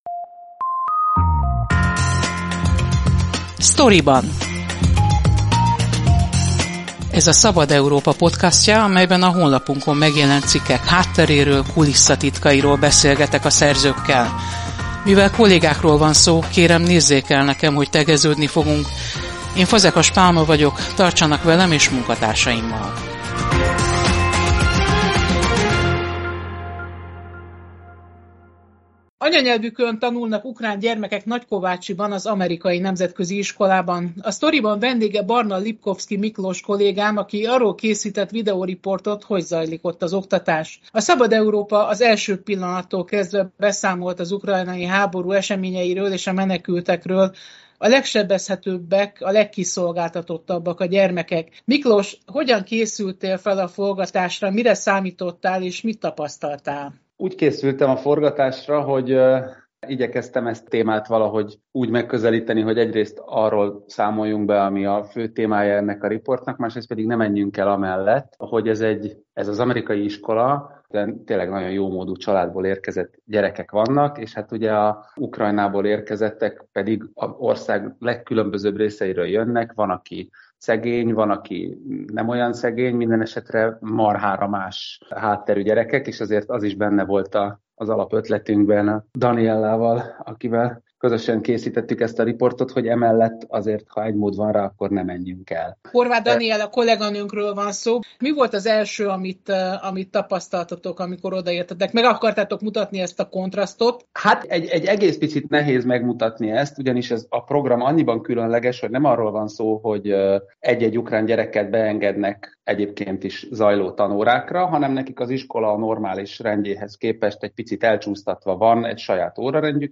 a menekült gyermekeket befogadó amerikai iskolában